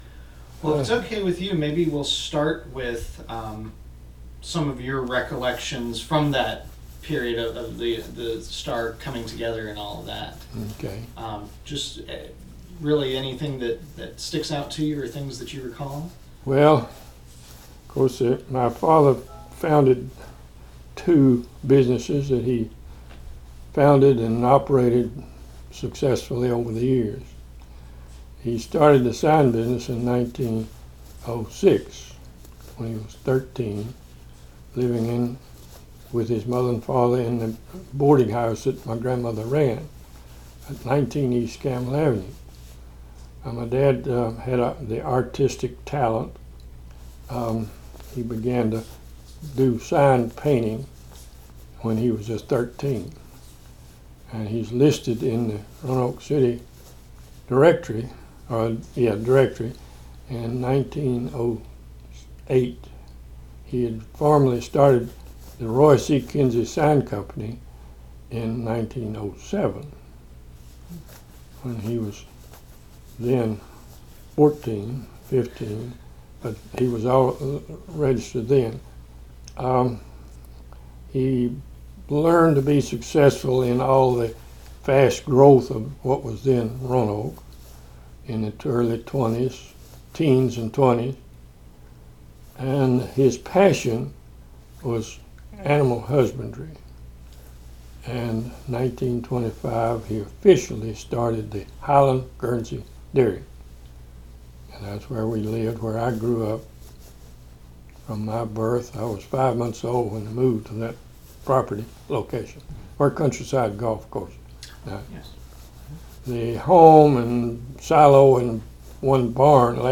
full, unedited interview